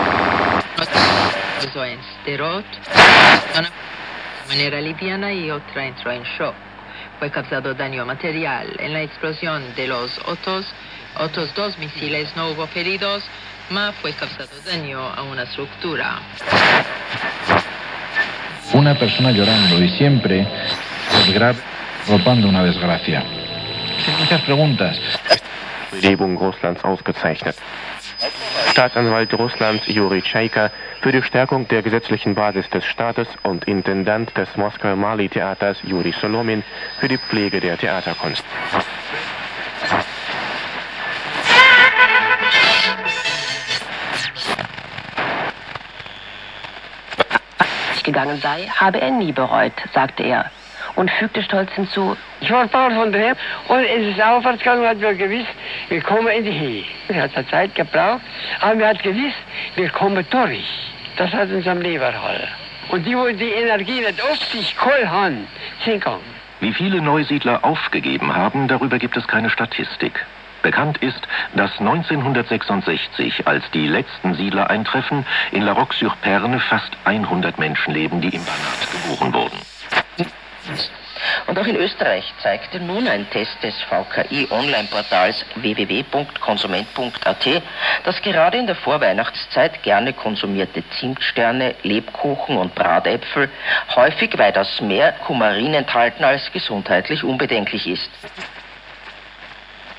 Art / Typ: 4‑Band Kofferradio Philips Tornado 860 (U‑K-M‑L)
Zu meiner Verblüffung ist die Faszination noch unverändert vorhanden, heutzutage freilich ohne das brachiale Störfeuer seitens des weltanschaulichen Gegners:
100 Sekunden Kurzwelle    (mp3, 1.561 KB)